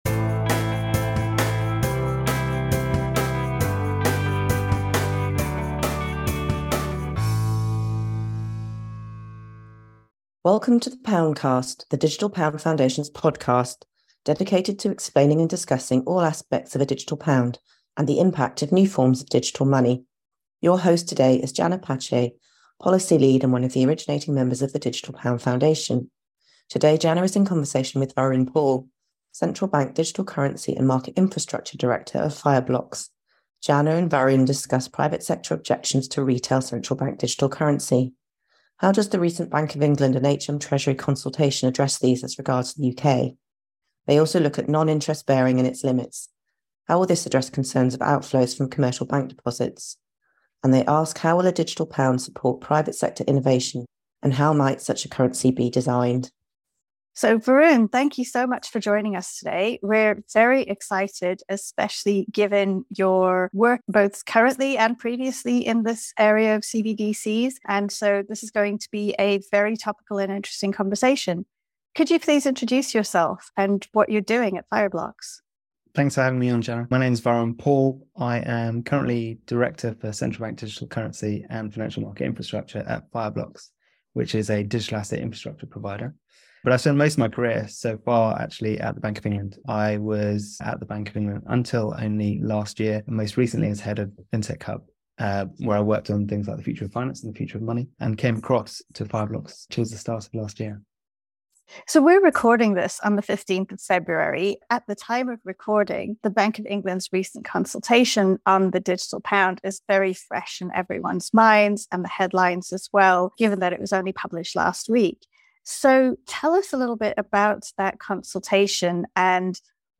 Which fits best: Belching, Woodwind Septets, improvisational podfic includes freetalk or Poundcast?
Poundcast